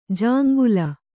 ♪ jāŋgula